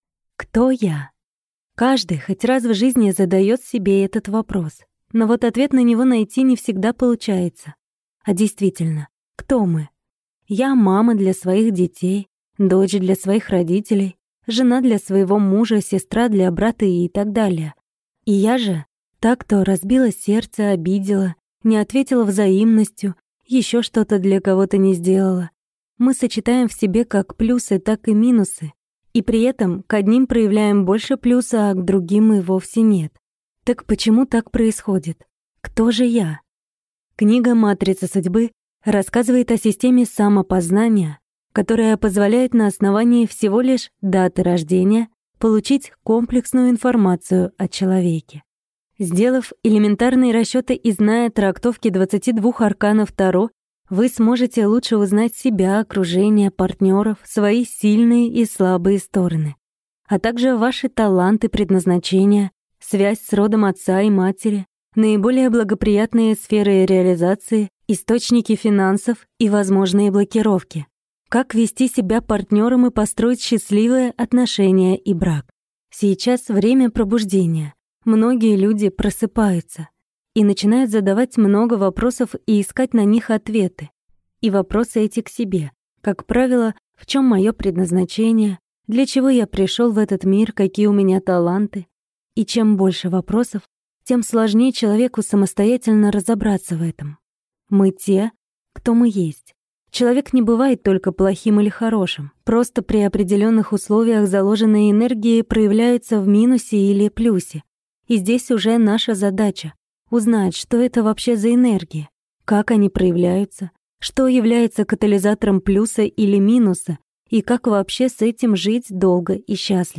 Аудиокнига Матрица судьбы. От А до Я | Библиотека аудиокниг
Прослушать и бесплатно скачать фрагмент аудиокниги